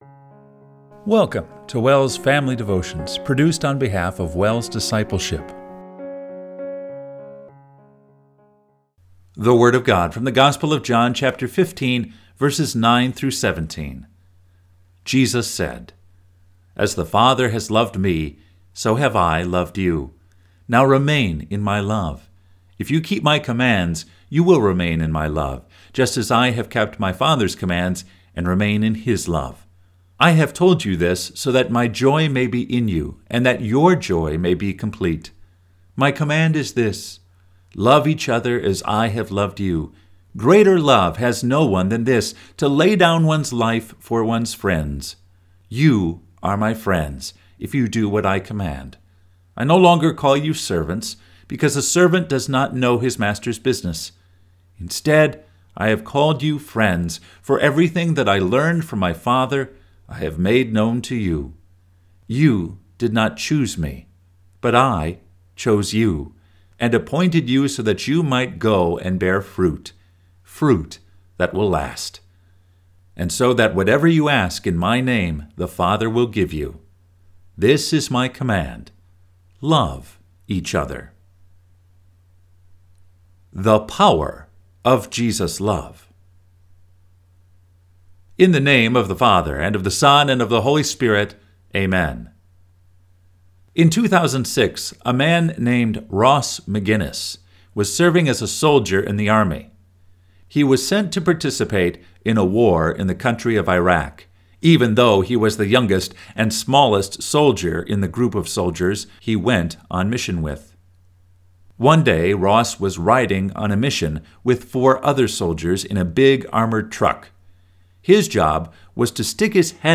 Family Devotion – May 8, 2024